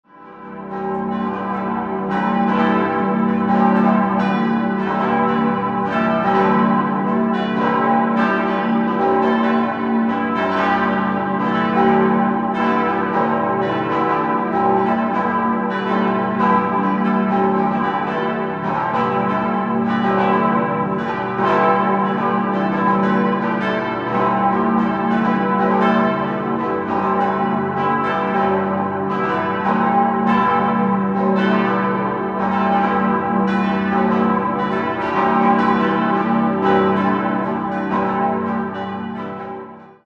Die Stadtpfarrkirche mit dem hohen Turm ist im spätgotischen Stil erbaut, das Langhaus wurde jedoch erst 1523 vollendet. In der Barockzeit erfolgte eine Neuausstattung des Gotteshauses. 5-stimmiges Geläute: g°-h°-d'-e'-fis' Die Glocken wurden 1952/53 von Karl Czudnochowsky in Erding gegossen.